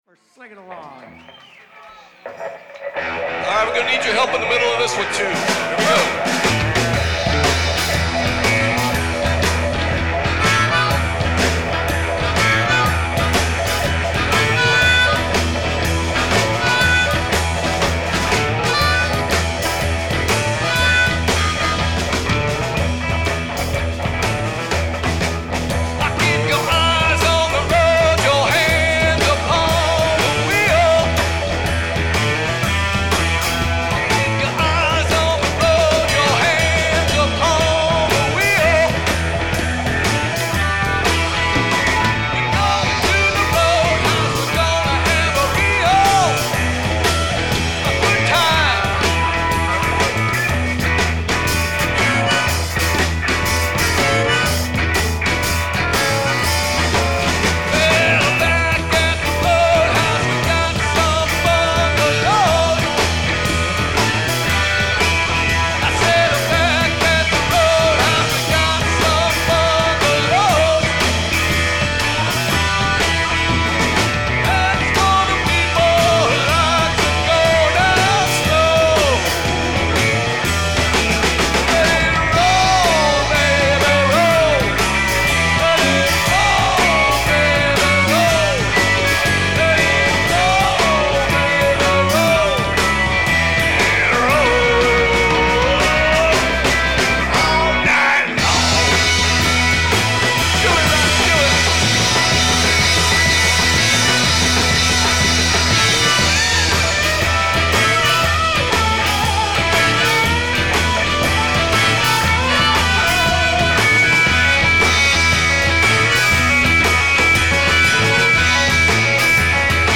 lead guitar and vocals
lead vocals, harmonica and percussion
bass guitar and vocals
drums